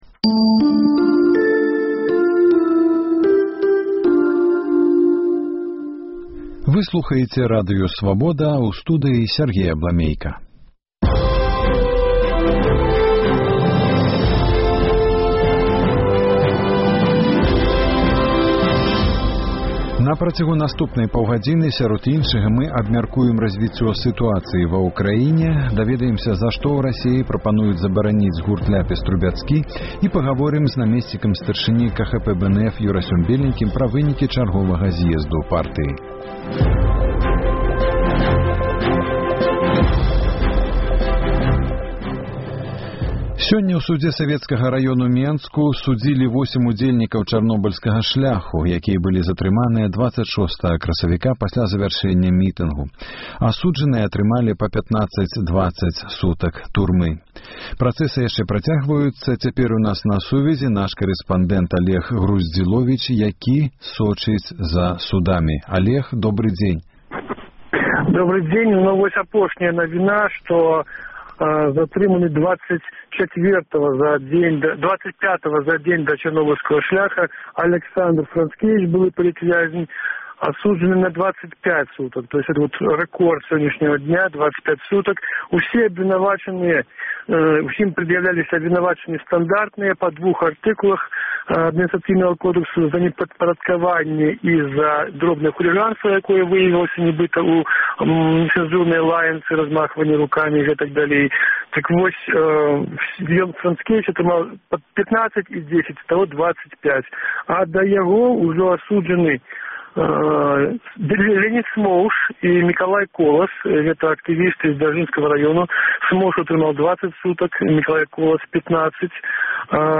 Як у Кіеве камэнтуюць новыя санкцыі і чаму прабуксоўвае антытэрарыстычная апэрацыя на ўсходзе Ўкраіны? Пра гэта – гутарка зь беларускай журналісткай у Кіеве